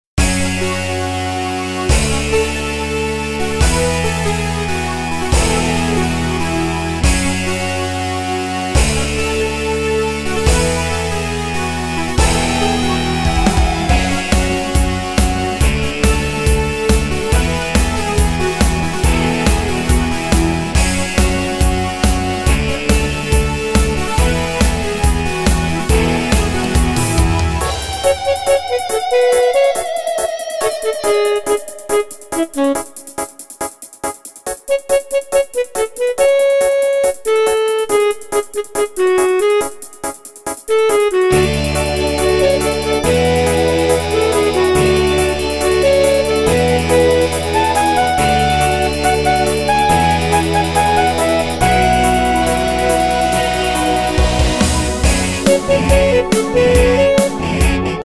Tempo: 140 BPM.
MP3 with melody DEMO 30s (0.5 MB)zdarma